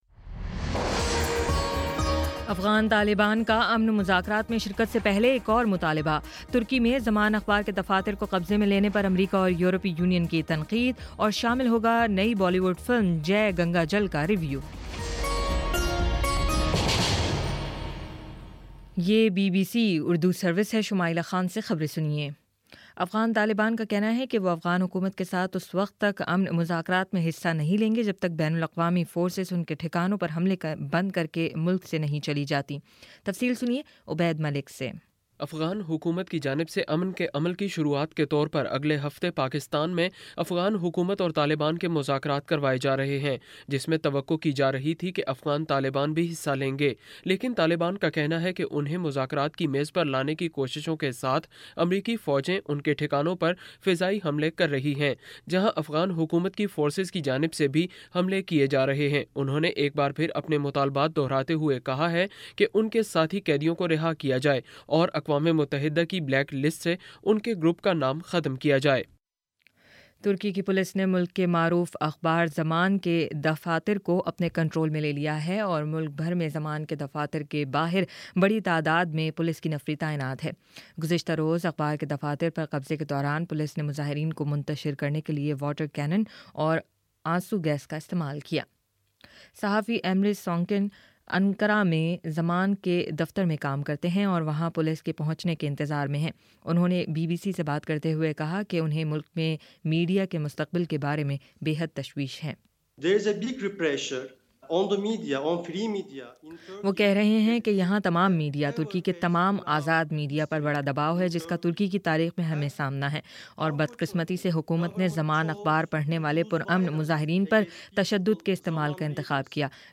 مارچ 05: شام سات بجے کا نیوز بُلیٹن